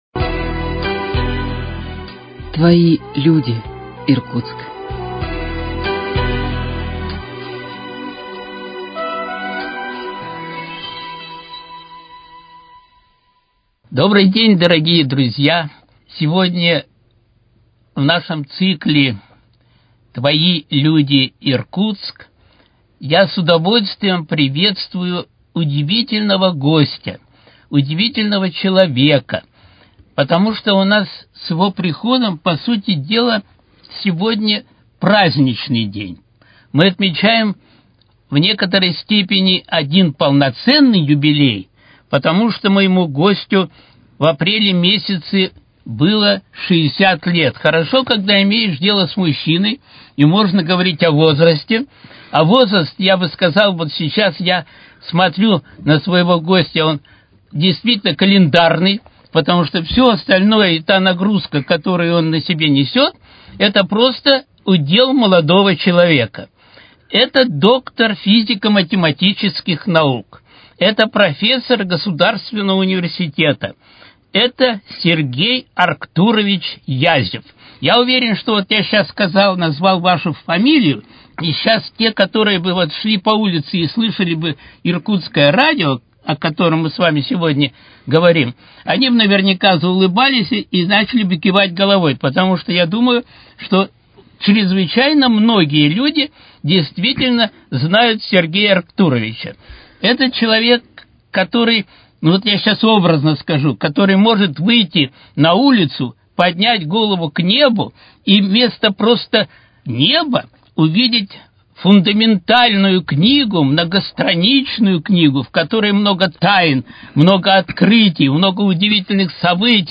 Твои люди, Иркутск: Беседа